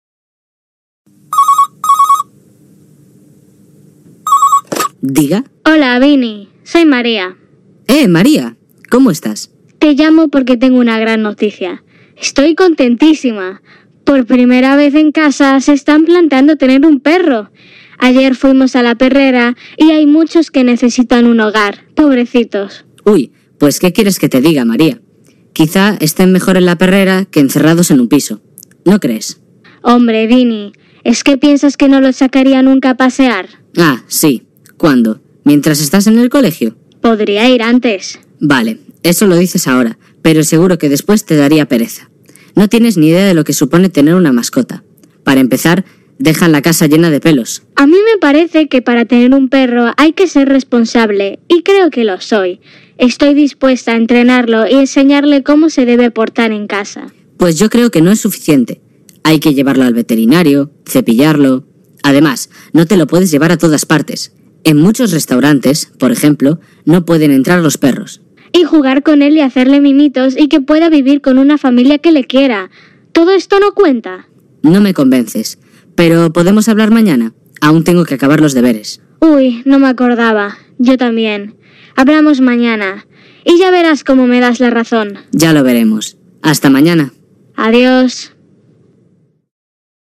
ESCUCHAMOS UNA DISCUSIÓN